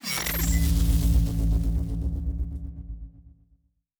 pgs/Assets/Audio/Sci-Fi Sounds/Electric/Shield Device 4 Start.wav at master
Shield Device 4 Start.wav